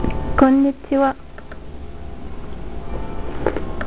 Prononciation: "Kon'nitchi oua".
konnichiha.aif